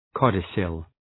Προφορά
{‘kɒdısəl}